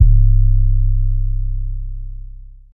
kits/TM88/808s/BWZYBd_7.wav at 32ed3054e8f0d31248a29e788f53465e3ccbe498